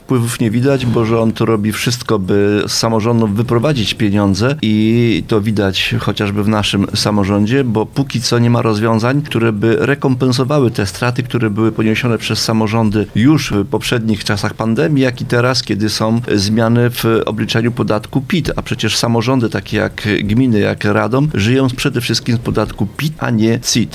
Poproszony o komentarz słów posła Marka Suskiego, który stwierdził, że samorządy również mają większe wpływy z podatków, radny Wędzonka dodaje: